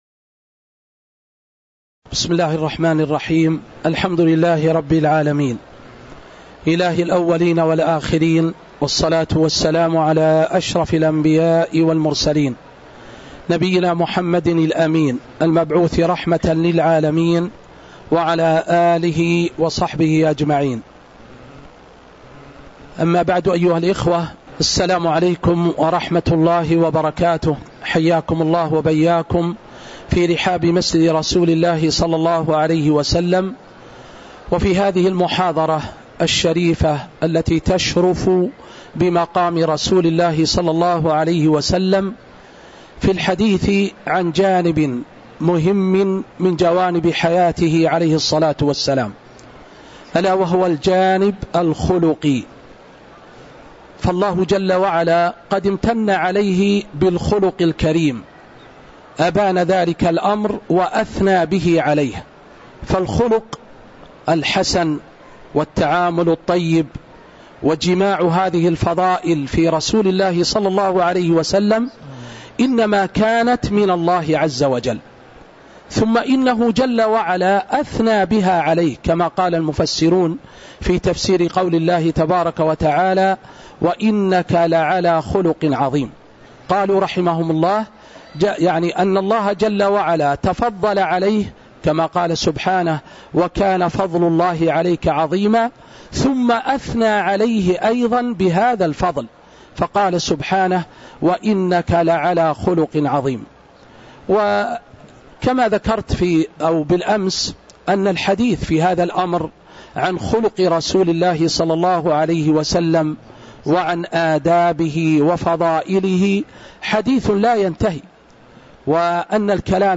تاريخ النشر ٢٣ جمادى الأولى ١٤٤٦ هـ المكان: المسجد النبوي الشيخ